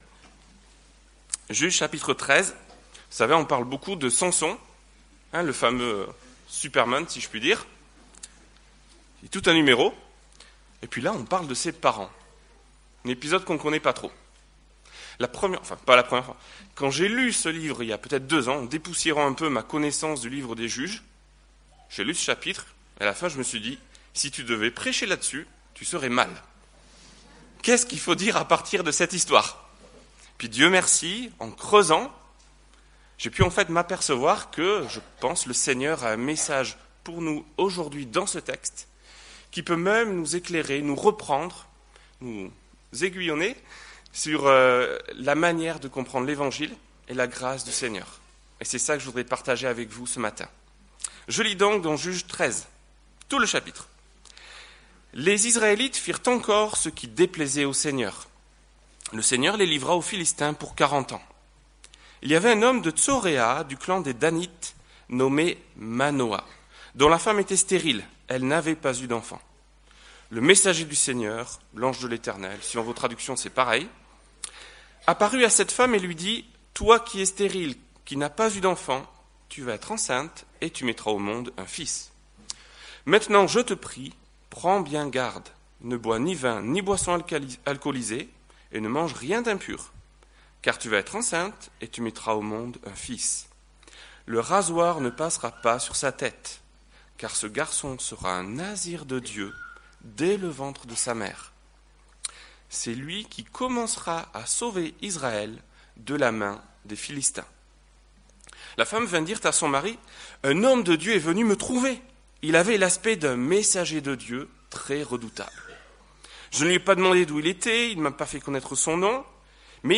Message culte d’ouverture 2013